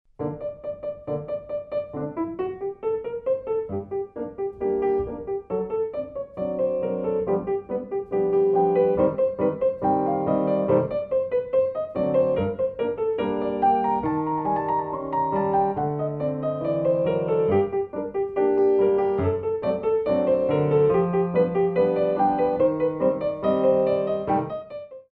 Déboulés